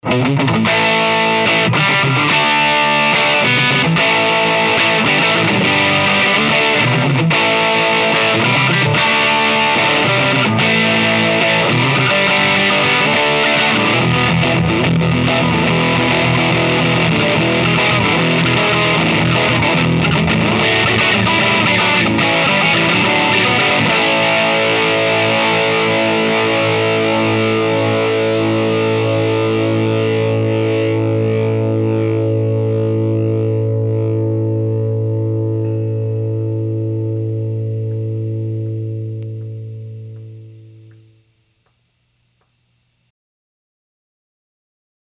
The second clip has no backing track, and features the Melt channel with a simple chord riff.
I had the Gain pushed up to about 3pm, which is almost all the way up. But even at a really high gain setting, you can still hear the individual notes.
By the way, both clips were played through the clean channel of my Aracom VRX22, and recorded at conversation levels using the incredible Aracom PRX150-Pro attenuator.